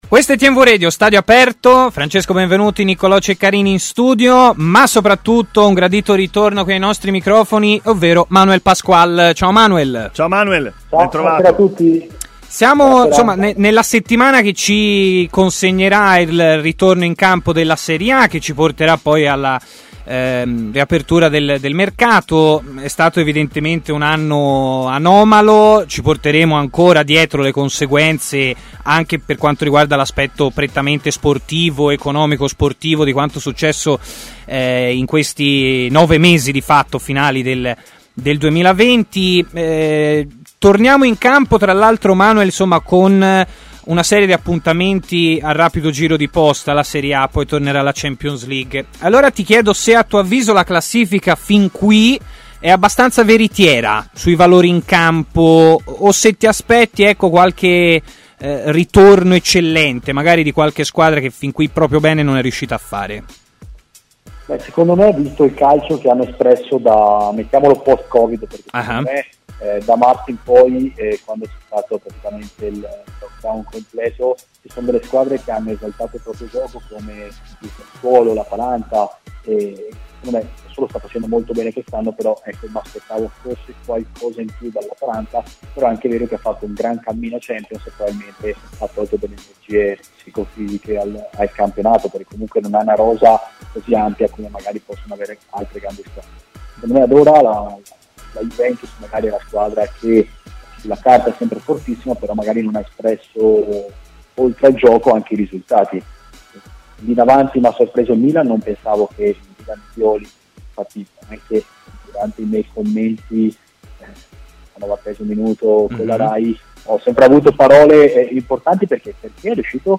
intervenuto a TMW Radio, nel corso della trasmissione Stadio Aperto